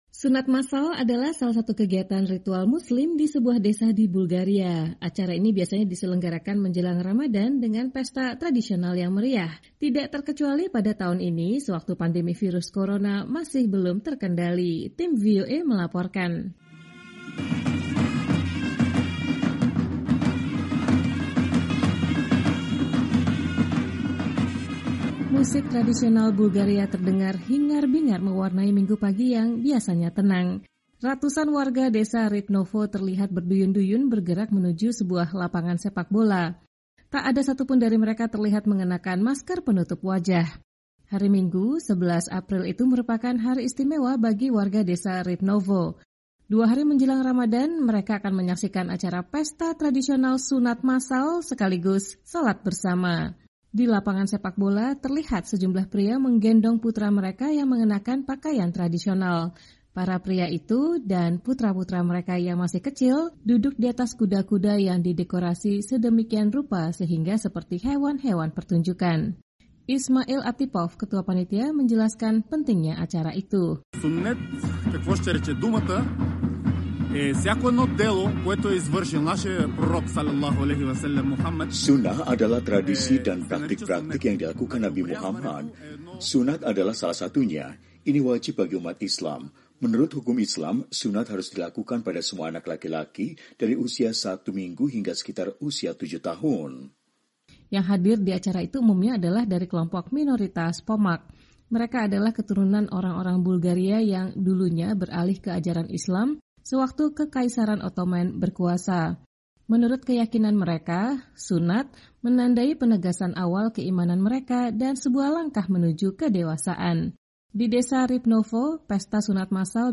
Musik tradisional Bulgaria terdengar hingar bingar mewarnai Minggu pagi yang biasanya tenang.
Tak heran, takbir terdengar berkumandang di pagi hari itu.